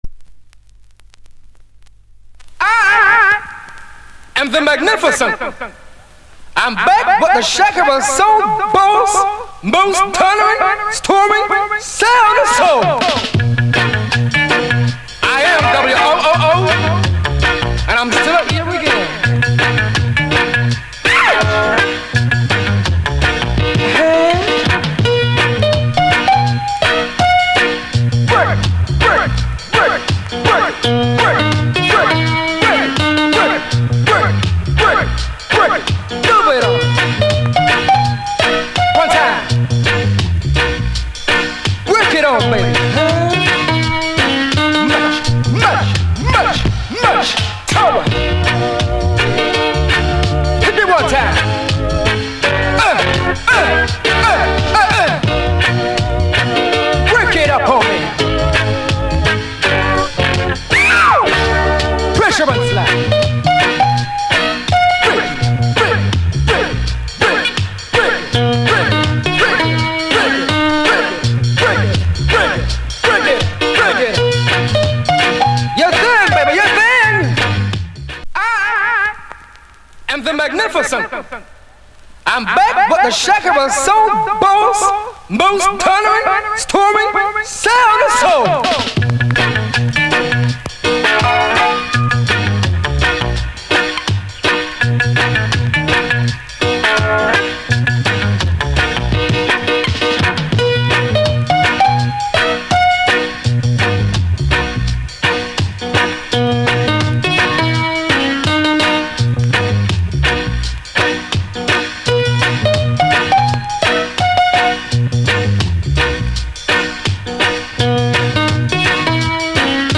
* Big Shot Fuky Regay!!!